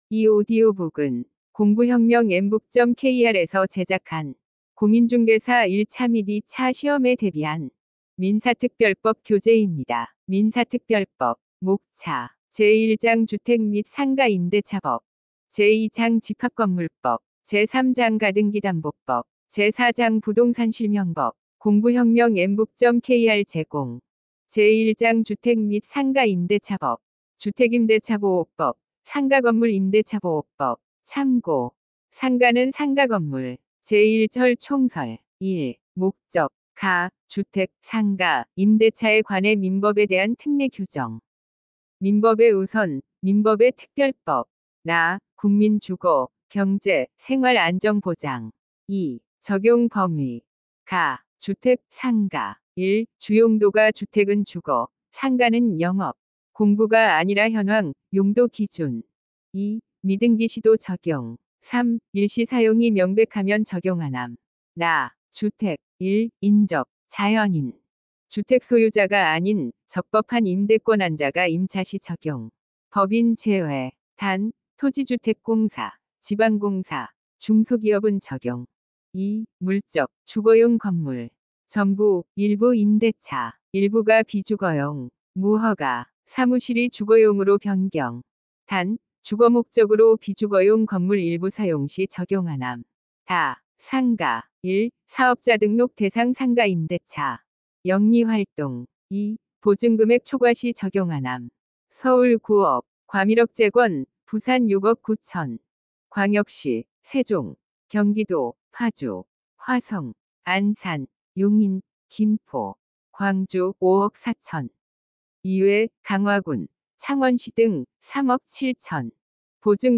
– 인공지능 성우 이용 오디오 학습 교재
(공인중개사 민사특별법 오디오북 샘플)
□ 엠북 인공지능 오디오북